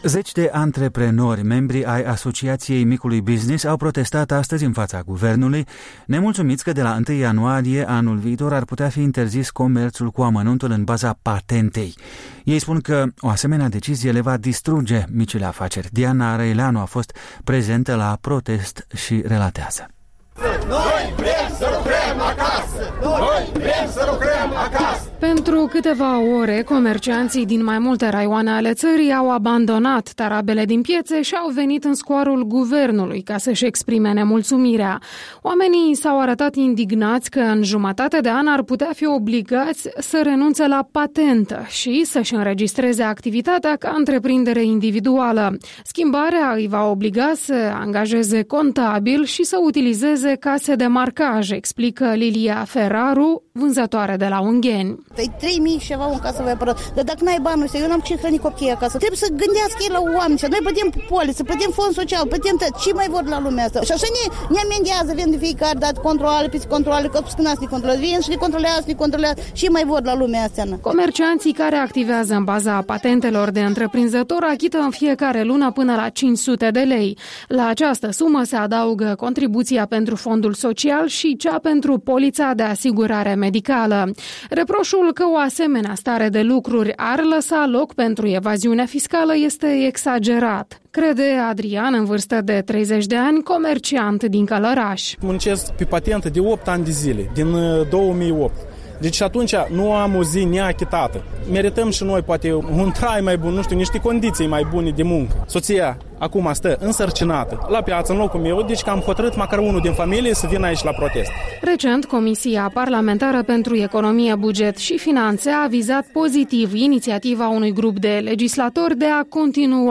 Protestul micilor întreprinzători la Chișinău (Video)